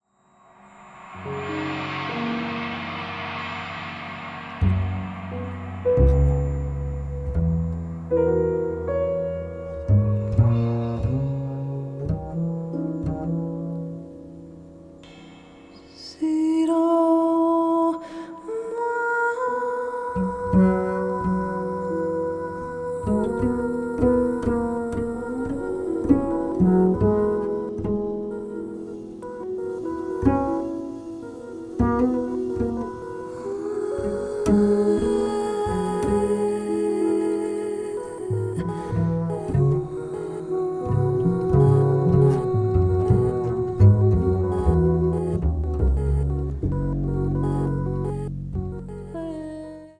彼らしい知的でハイセンスなサウンドが展開する”大人な”アルバムです。
voice and live electronics
acoustic bass, electric bass
piano, Fender Rhodes, keyboards
drums and percussions